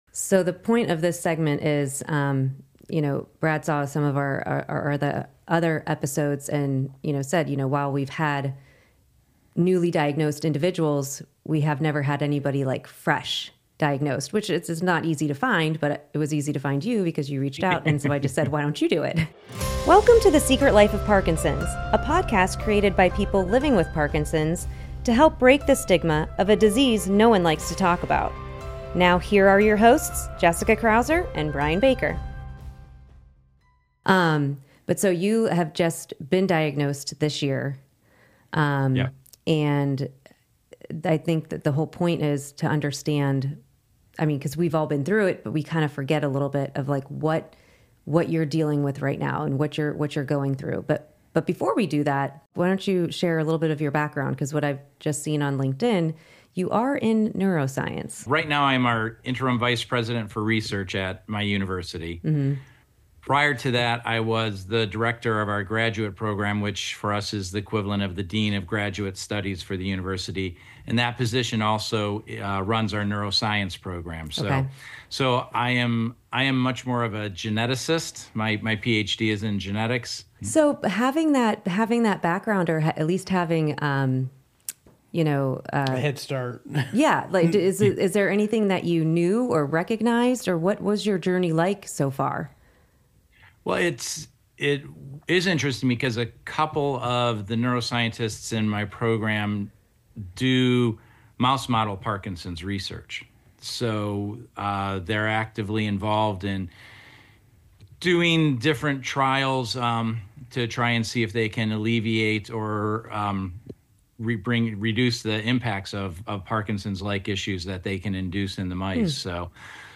1 Understanding the Experience of LGBTQ+ People Living with Parkinson’s Disease 45:03 Play Pause 2d ago 45:03 Play Pause Play later Play later Lists Like Liked 45:03 Life with Parkinson’s disease (PD) is shaped by myriad factors, including layers of identity and background that shape access to care, lifestyle decisions and more. In this episode of The Michael J. Fox Foundation’s Parkinson’s Podcast, our expert panel shares their personal stories and discusses the ongoing research into the health concerns and ne…